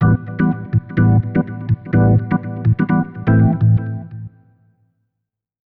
ORGAN010_VOCAL_125_A_SC3(R).wav
1 channel